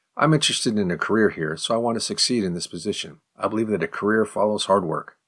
08_advanced_response_fast.mp3